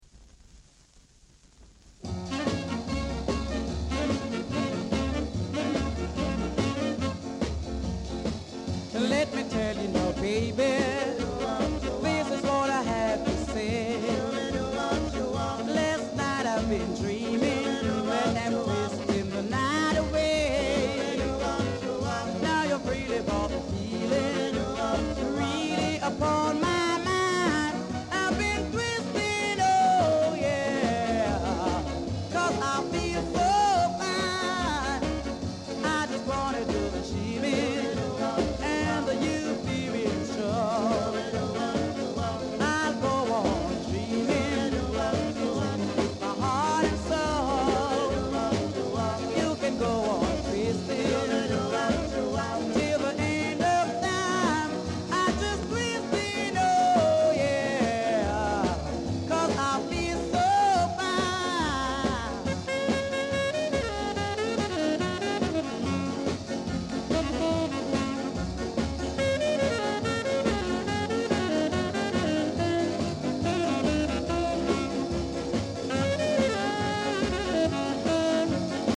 Ska Male Vocal
Great early ska vocal w-sider!